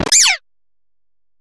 Cri de Poussacha dans Pokémon HOME.